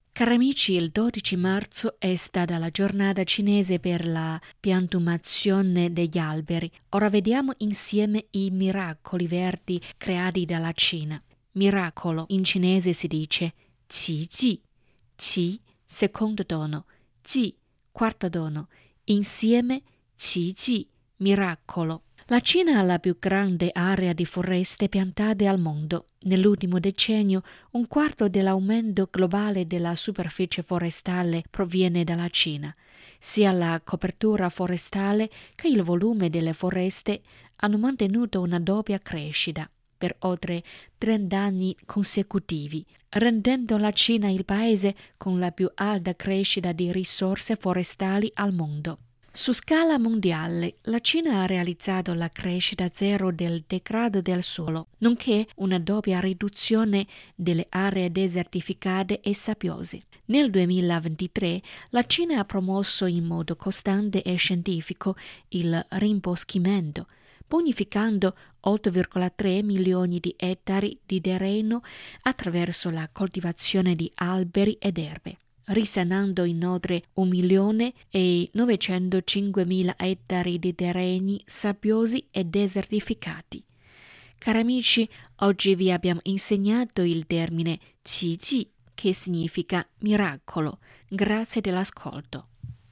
Ora vediamo insieme i miracoli verdi creati dalla Cina. “Miracolo” in cinese si dice “Qi ji”, qi, secondo tono, ji, quarto tono, insieme qi ji, miracolo. La Cina ha la più grande area di foreste piantate al mondo.